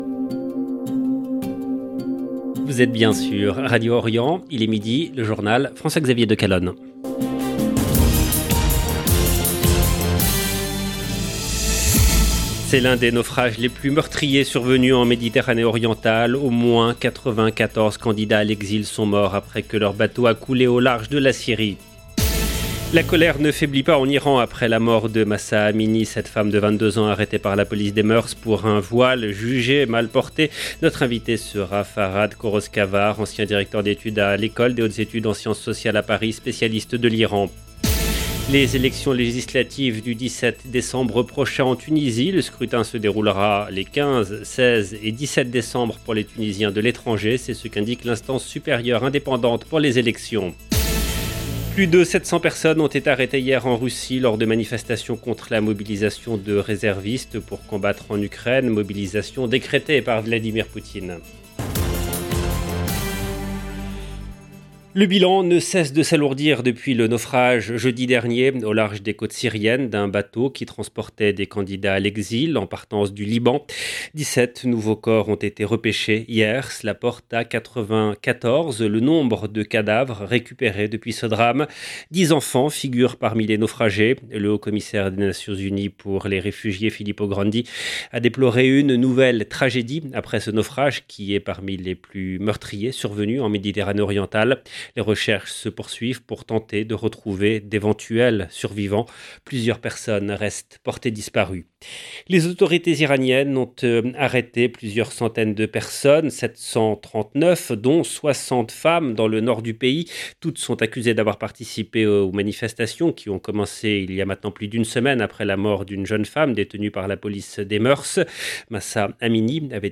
EDITION DU JOURNAL DE 12H EN LANGUE FRANCAISE DU 25/9/2022
Notre invité sera Farhad Khosrokhavar, ancien directeur d'études à l'École des hautes études en sciences sociales à Paris, spécialiste de l'Iran.